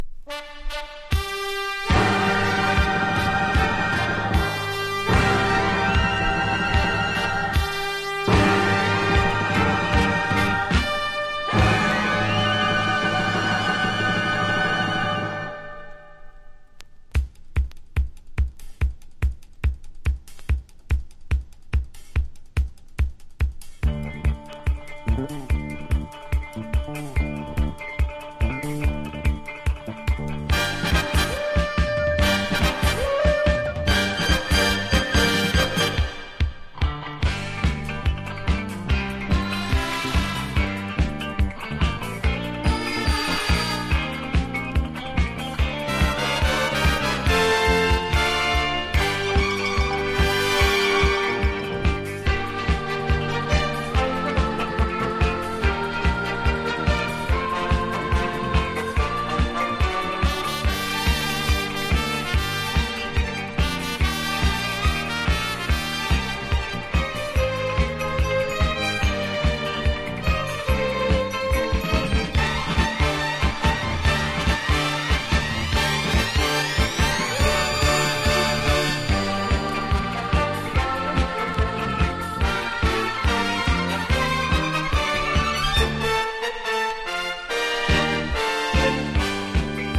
ディスコ・オーケストラなメイン・テーマなどゴージャスなアレンジです!!
# DISCO# CLUB# 和モノ